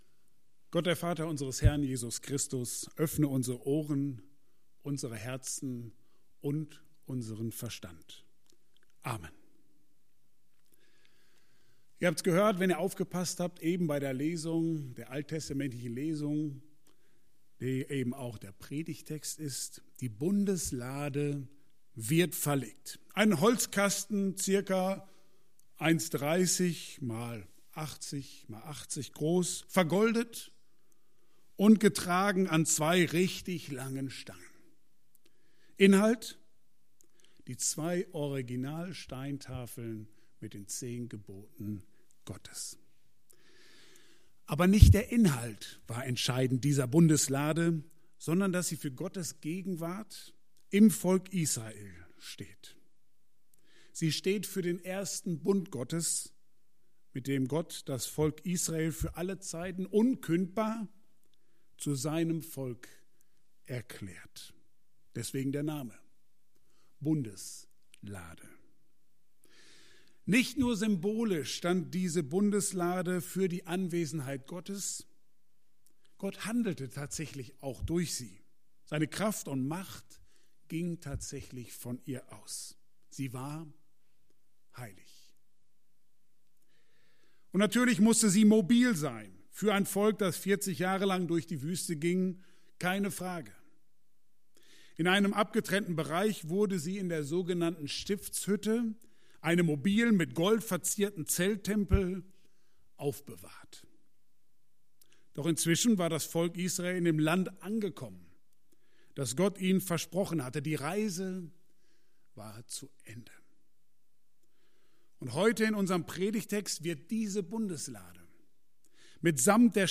Mai 2020 Der Ton macht die Musik & Gottes Anwesenheit im Gottesdienst Prediger